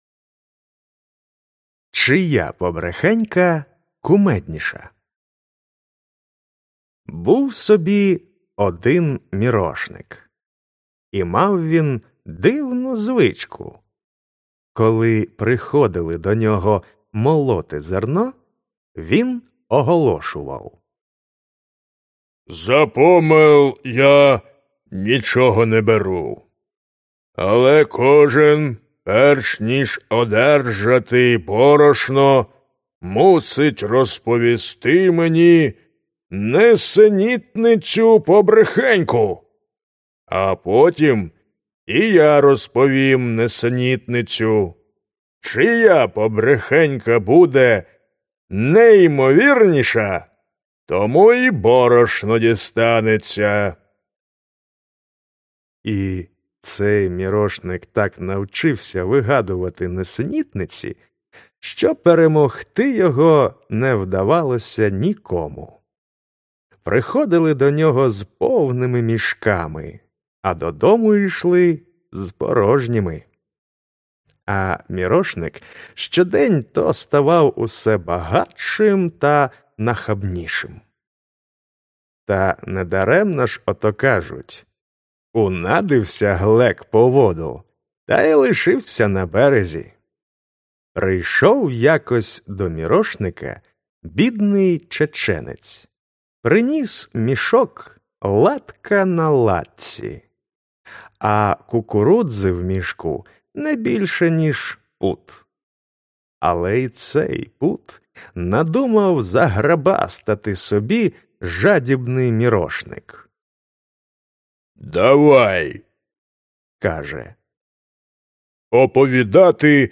Аудіоказка Чия побрехенька кумедніша?